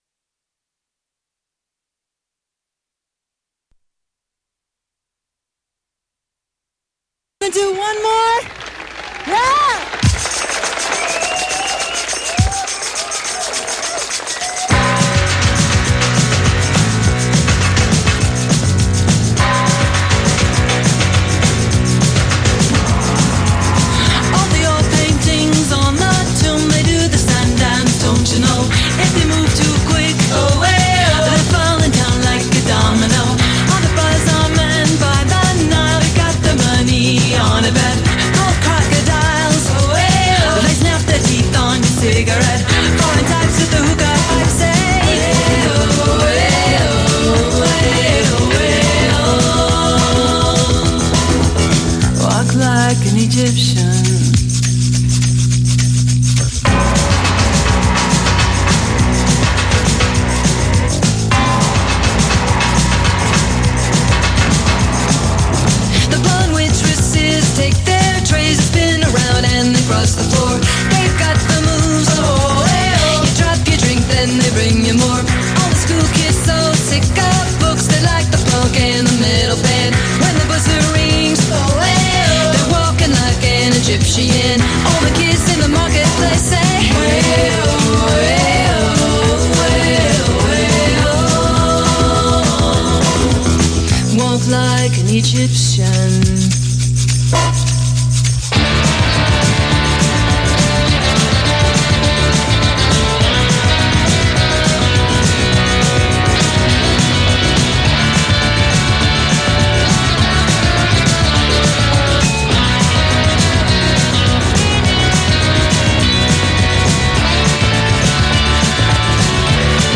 Ora invece c’è, ed il merito è di Marco Furfaro, deputato del PD, che abbiamo intervistato.